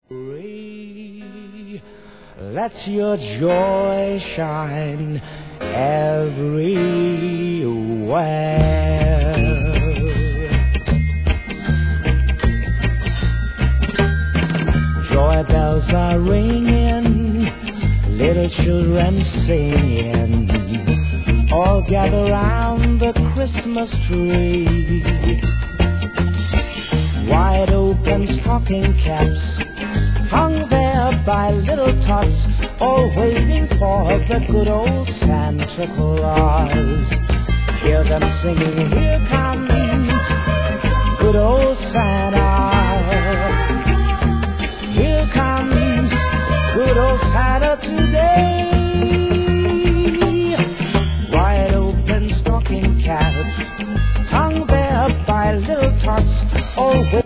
REGGAE
1973年、クリスマス定番ソングをレゲエ・カヴァー♪